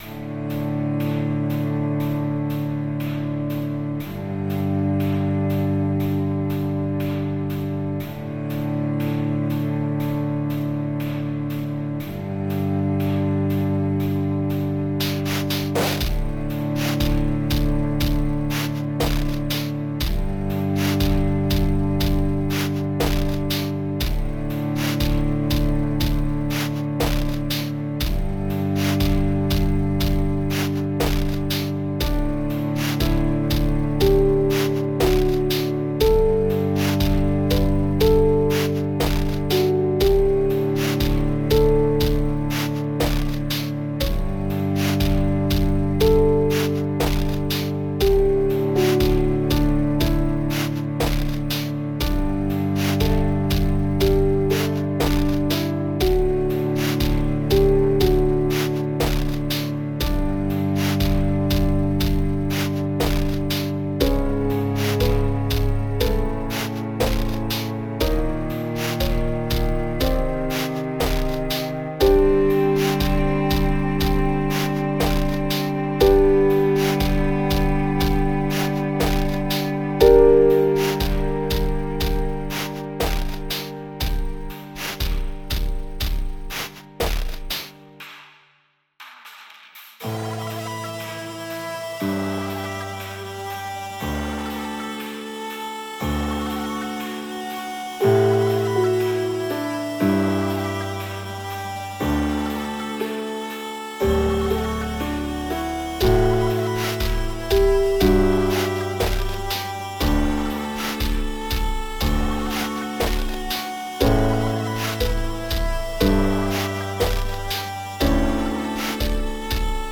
Industrial-Classical Fusion